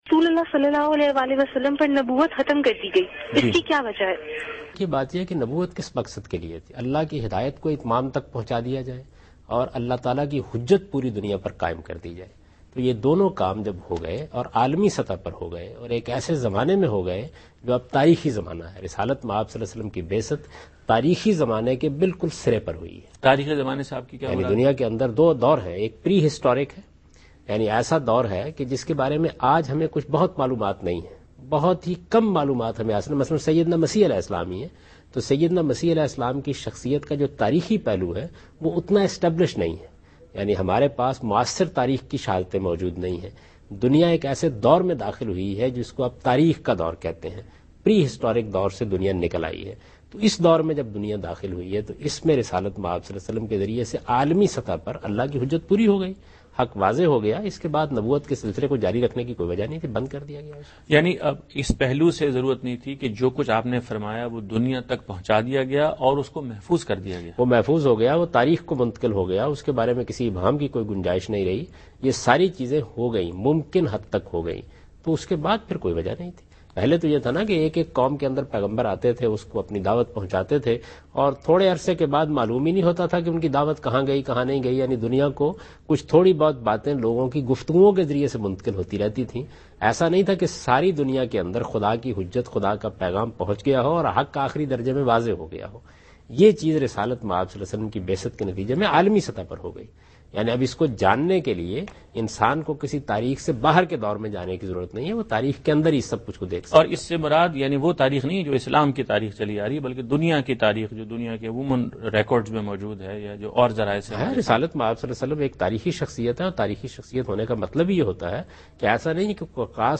Category: TV Programs / Dunya News / Deen-o-Daanish /
Javed Ahmad Ghamidi answers a question regarding "Reason of Ending Prophethood" in program Deen o Daanish on Dunya News.